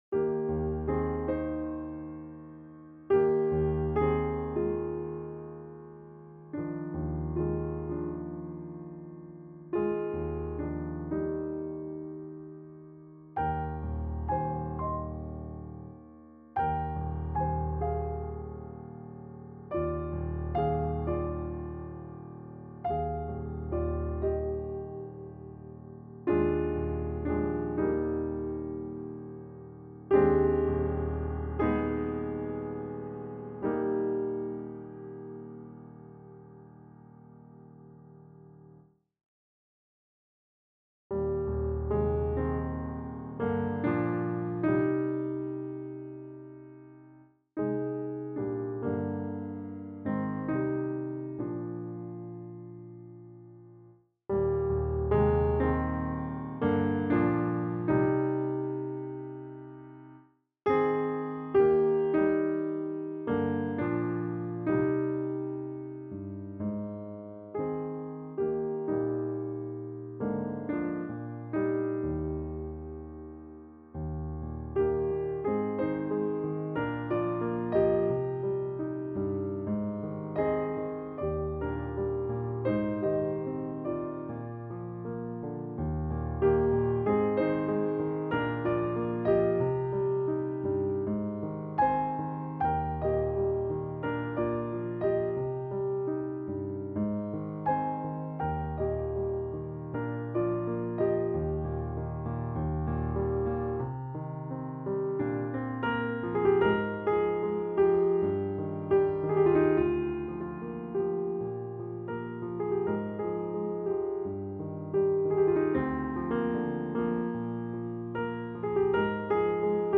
No. 31 "If I Loved You Less" (Piano